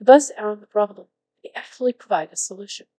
coqui-tts - a deep learning toolkit for Text-to-Speech, battle-tested in research and production